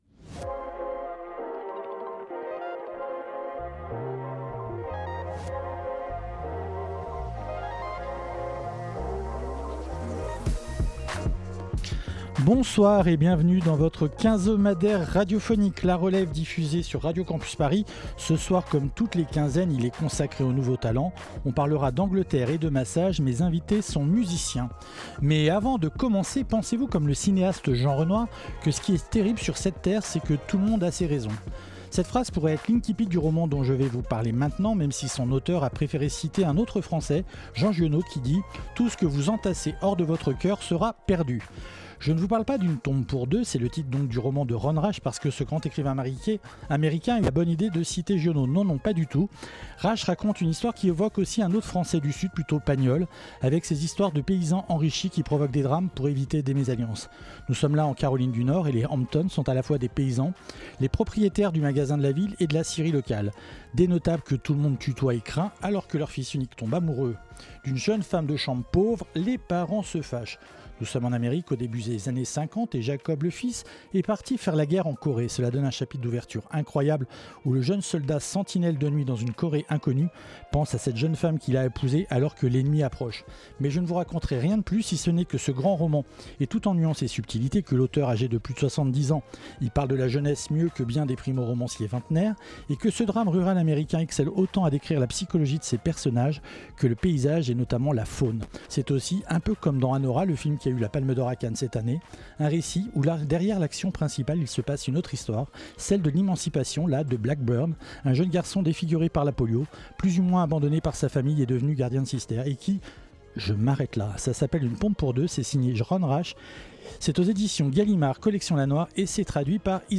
Nos deux invités sont musiciens.
massages pour pianos et musique anglaise Partager Type Entretien Culture lundi 9 décembre 2024 Lire Pause Télécharger Nos deux invités sont musiciens.